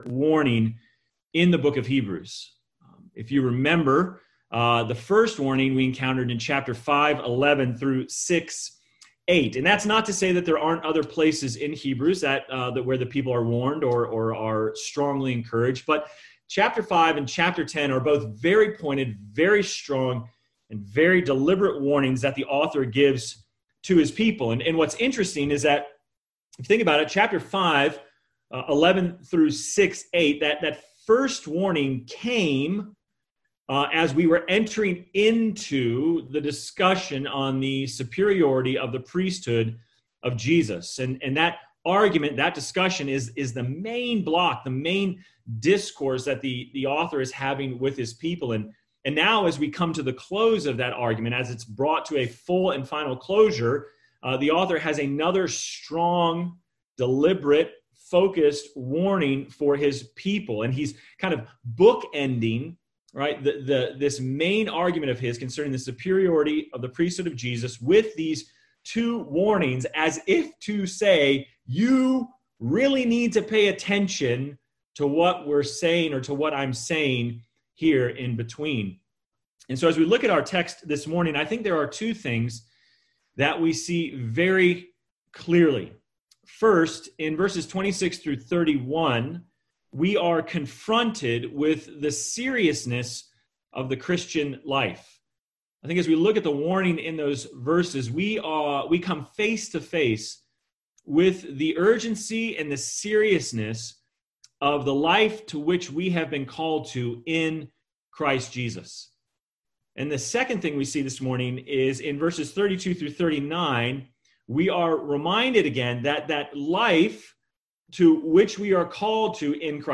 Sermon Text: Hebrews 10:26-39 First Reading: Deuteronomy 8:1-5, 32:44-47 Second Reading: Romans 5:18-6:14